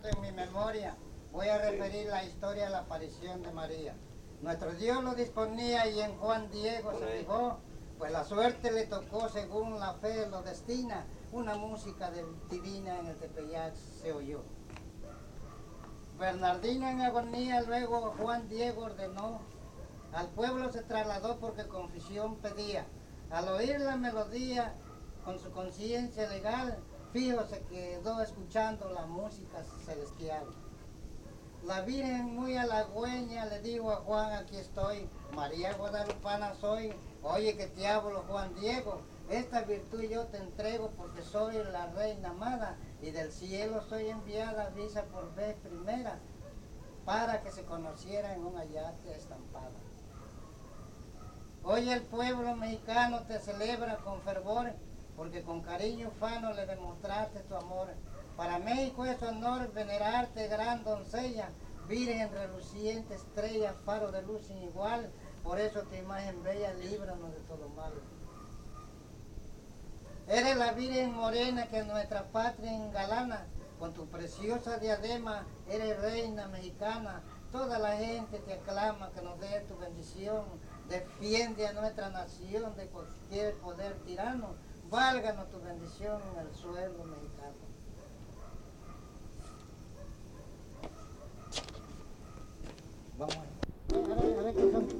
Fiesta de La Candelaria: investigación previa